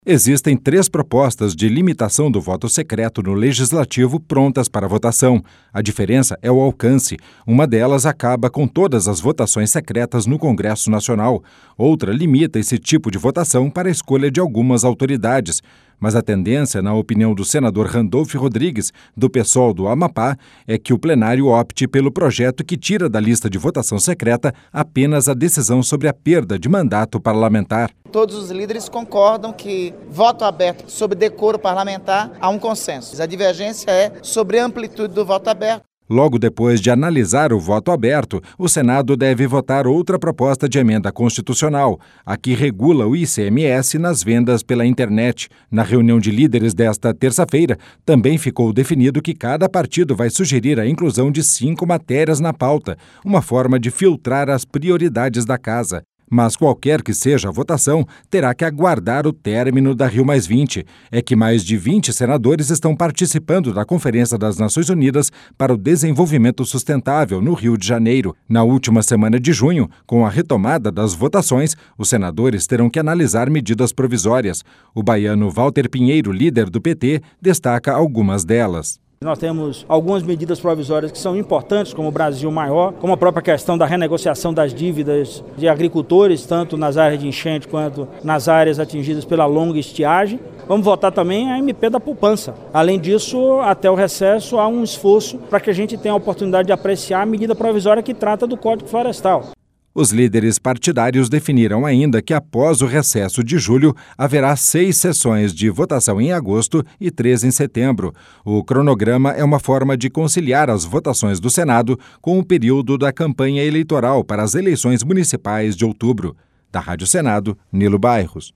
A REPORTAGEM